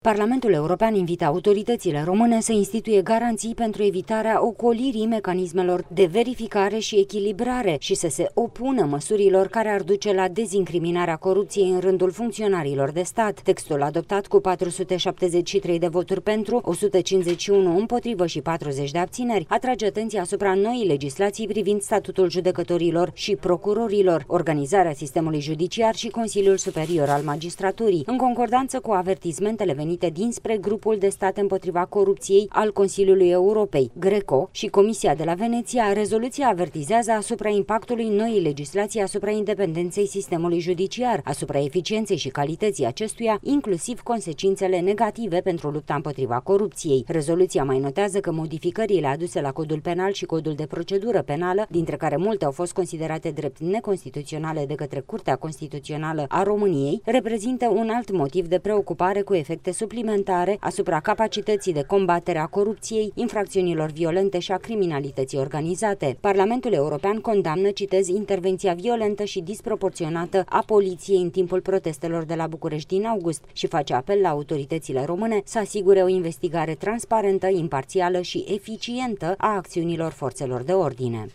relatează din Strasbourg